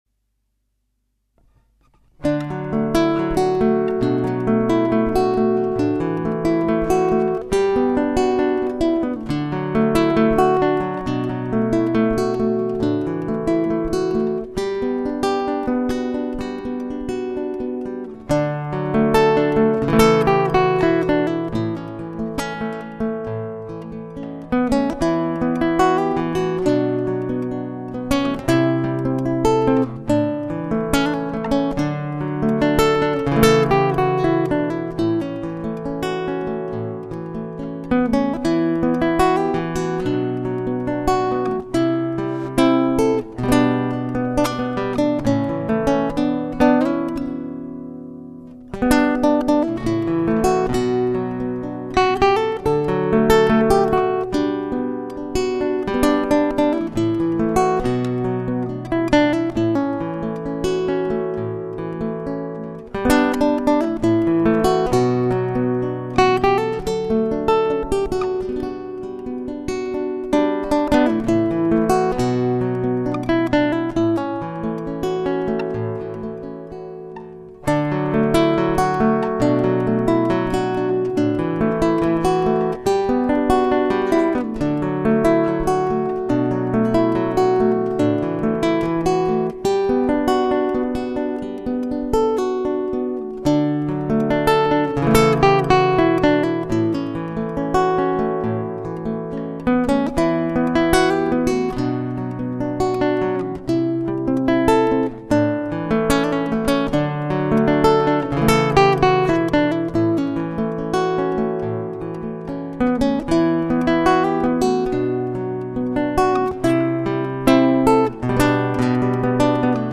My Music Demo Tracks